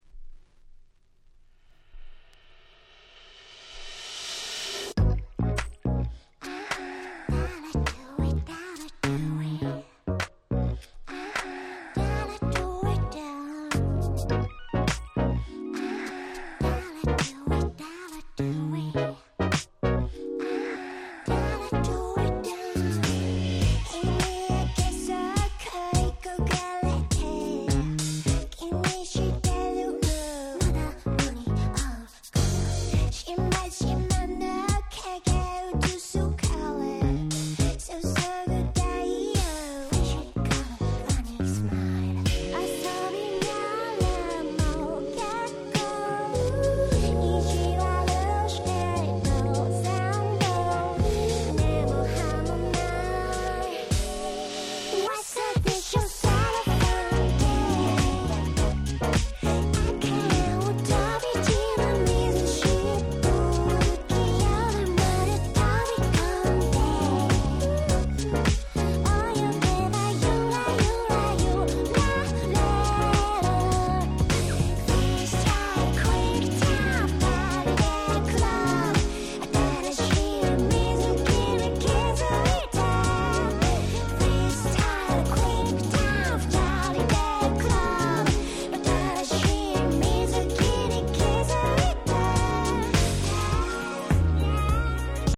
20' Super Nice J-Pop !!